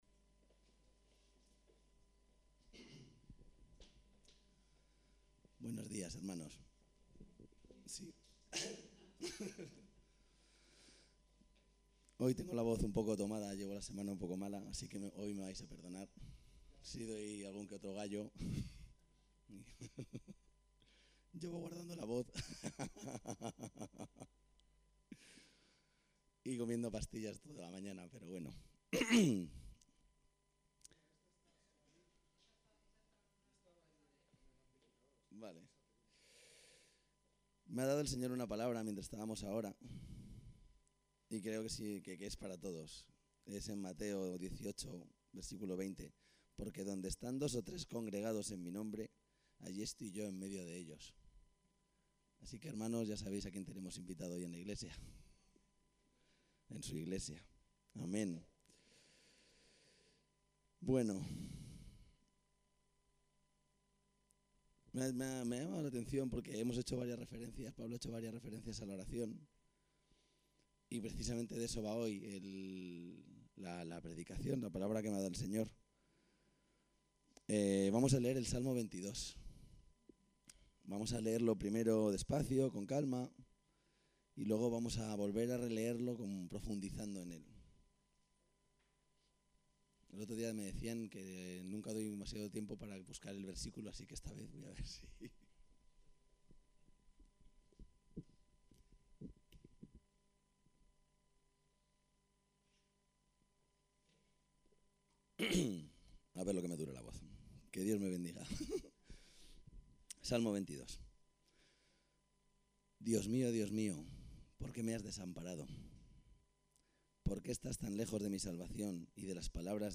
Predicación en texto: La oración y como enfrentrase a la flaqueza de fuerzas